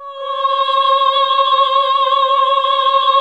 AAH C#3 -R.wav